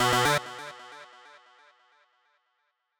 フリー効果音：システム45
システムSE系効果音、第45弾！ゲームやアプリに汎用的に使えそうな機械・システム音です！